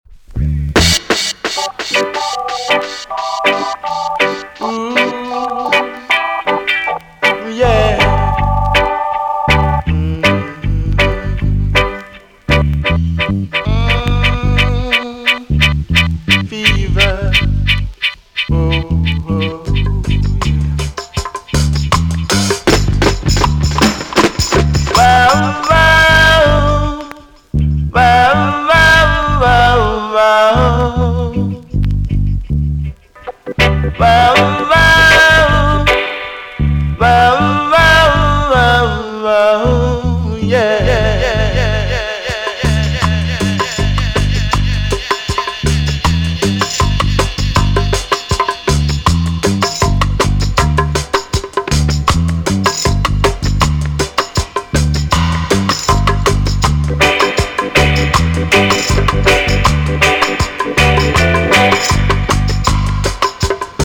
TOP >80'S 90'S DANCEHALL
EX-~VG+ 少し軽いチリノイズが入ります。